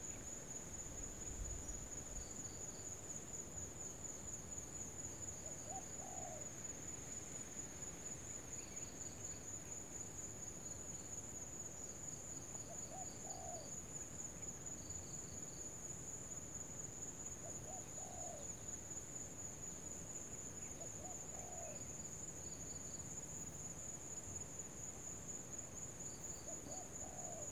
field_day.ogg